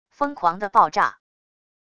疯狂的爆炸wav音频